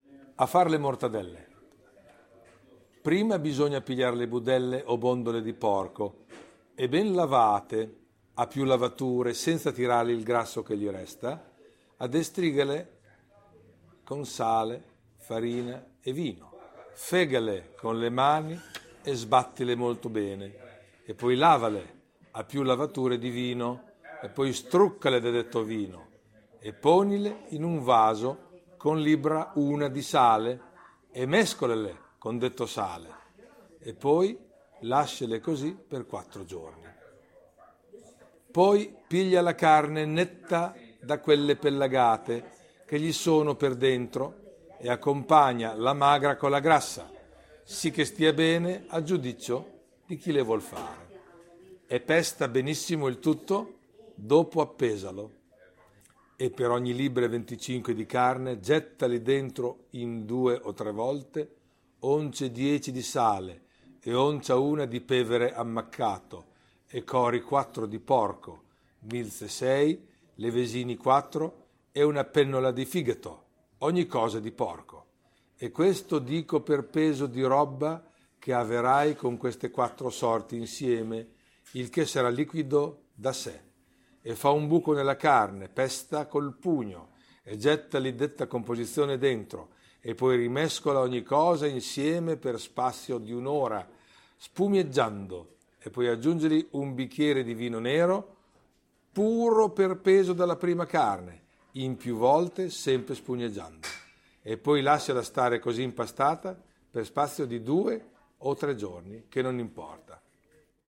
标签： 场记录 喷泉 室外 博洛尼亚 声景
声道立体声